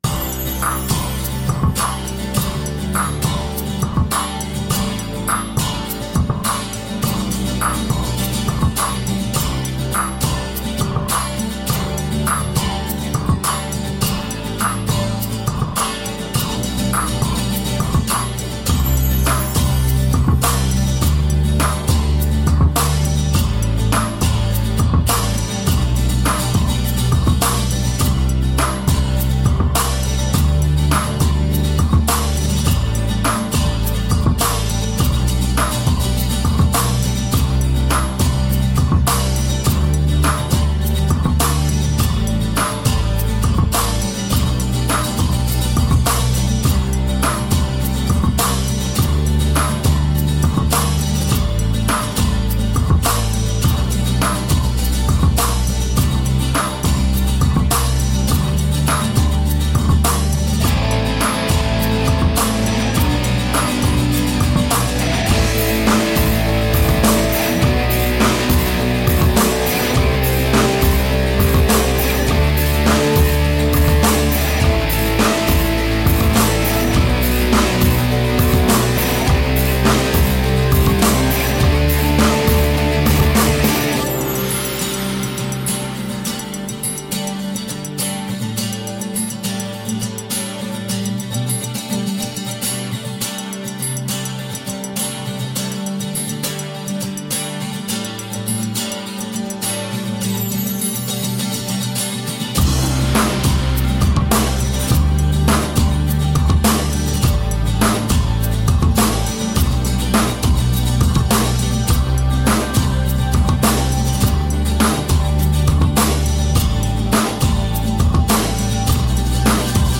Guitar-edged industrial electrorock.